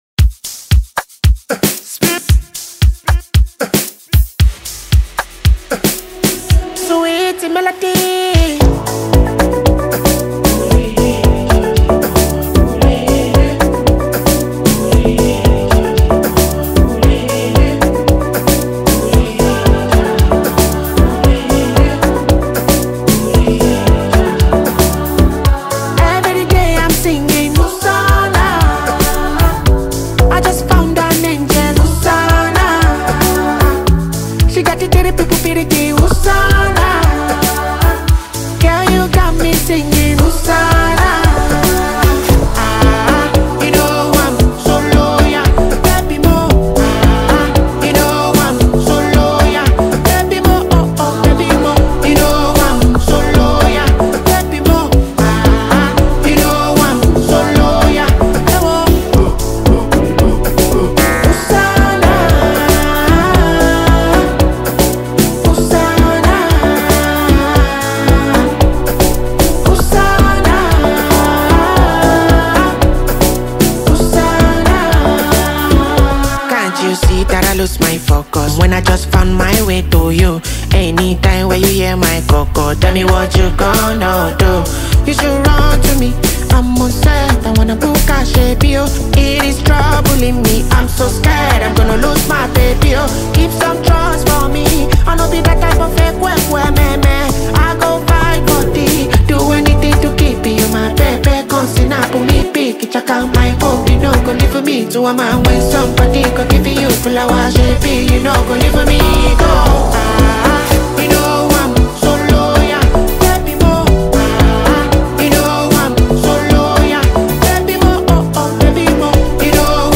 seductive new song